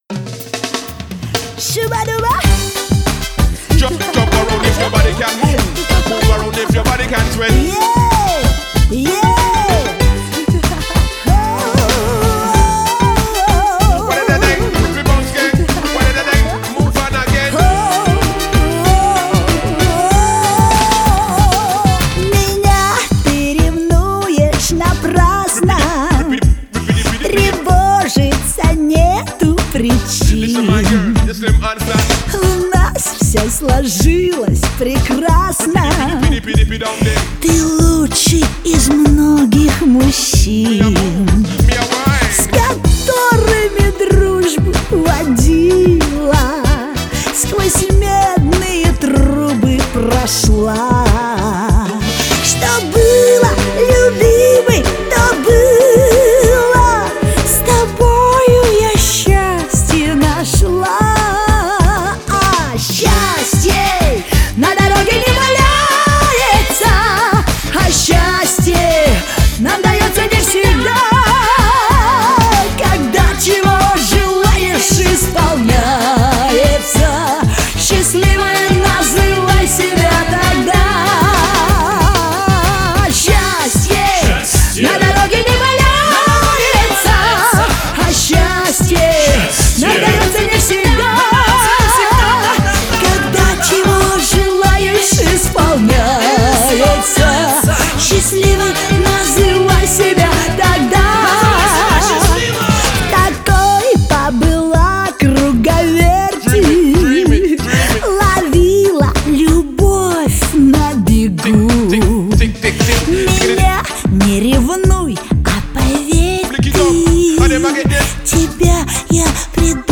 российская поп-певица